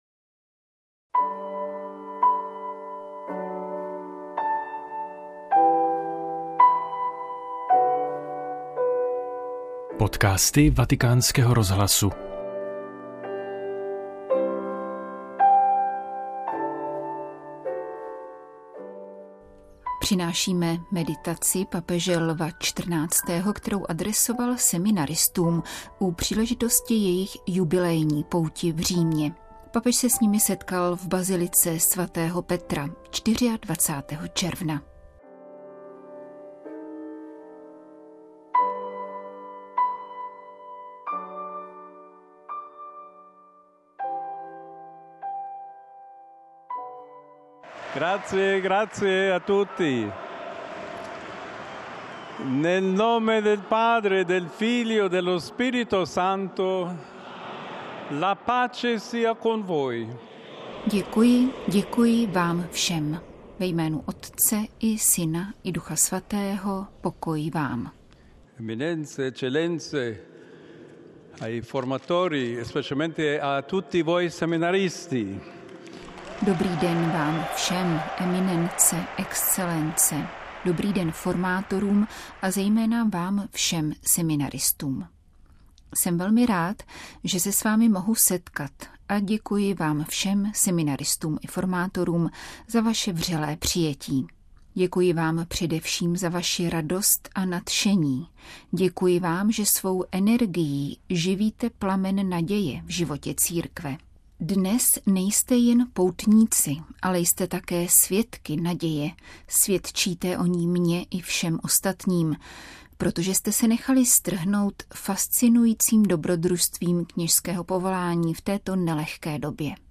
Katecheze-Lva-XIV.-o-povolani-ke-knezstvi-2025.mp3